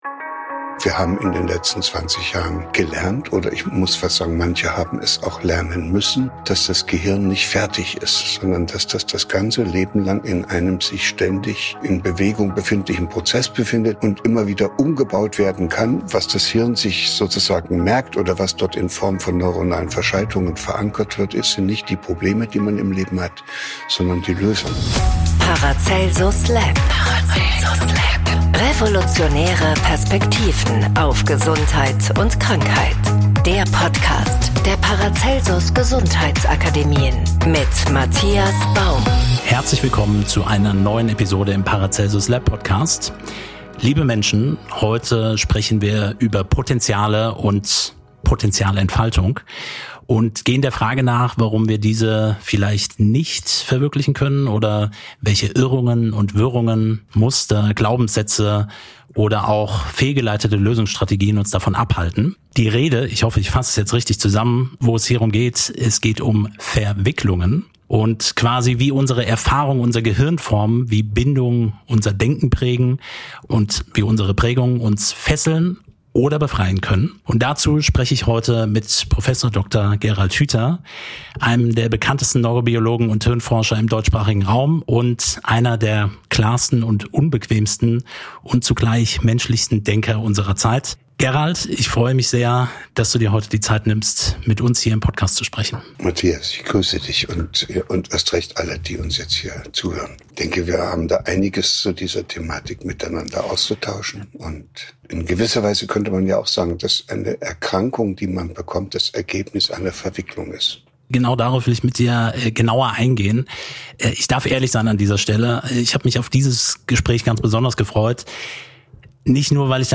spricht in dieser Episode mit Prof. Hüther über die tief verwurzelten inneren Muster, die wir häufig seit der Kindheit in uns tragen - sogenannte Verwicklungen. Sie beeinflussen unser gesamtes Leben: unsere Beziehungen, unsere Entscheidungen, unser Denken und sogar unsere körperliche Gesundheit.